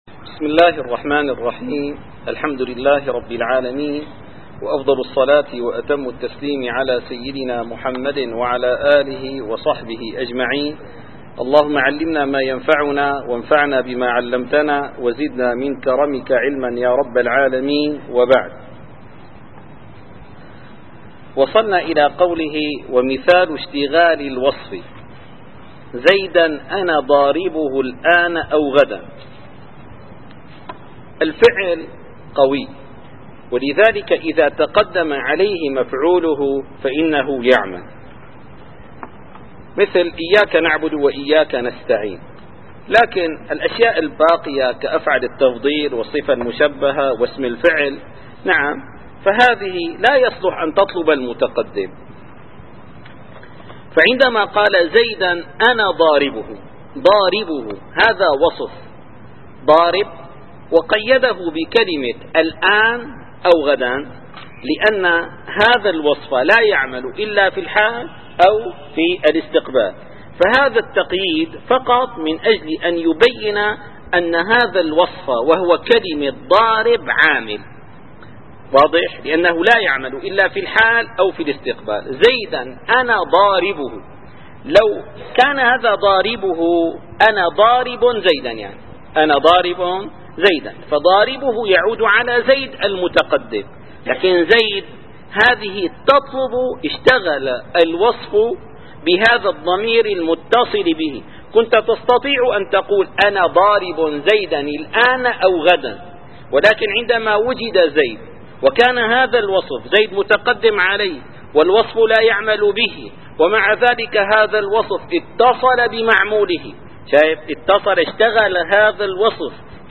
- الدروس العلمية - شذور الذهب في معرفة كلام العرب - ومثال اشتغال الوصف ص285-296.